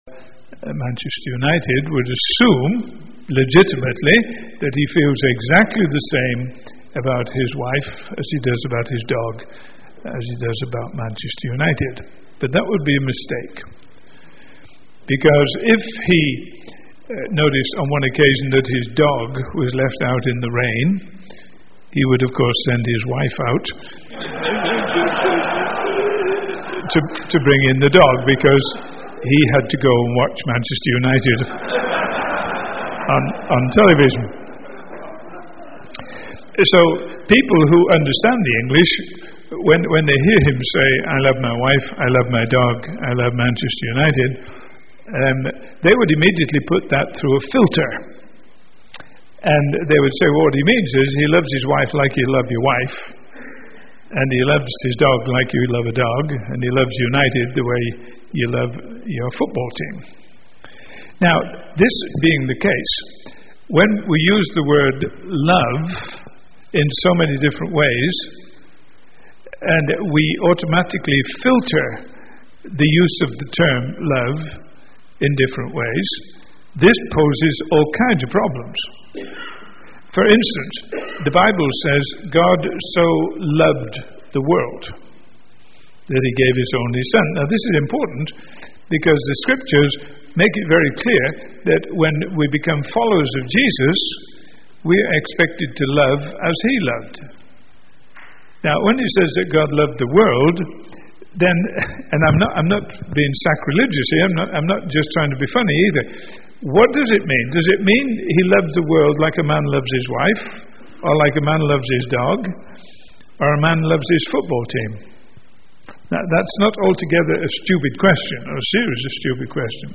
In this sermon, the speaker begins by discussing the challenges that young couples face in their marriages. He emphasizes that God designed marriage and there is a reason for the difficulties that arise.